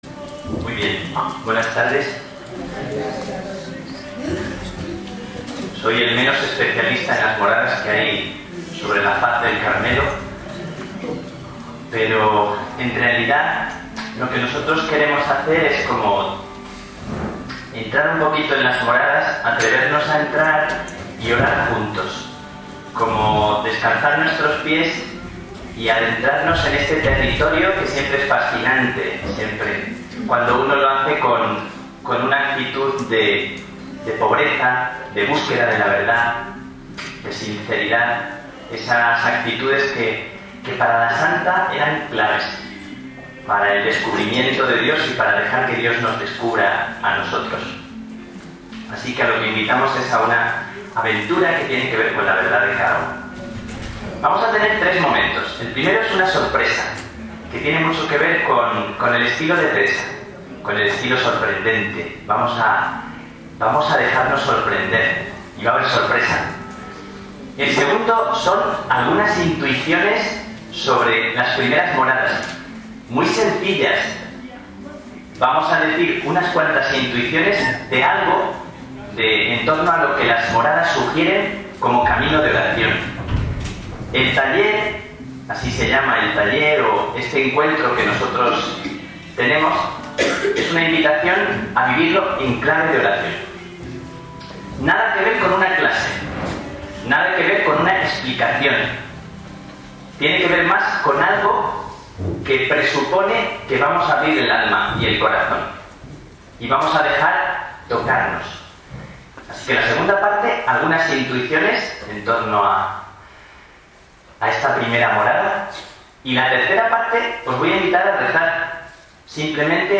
Audio Taller de Oración desde el Espíritu de Las Moradas – 1ª Sesión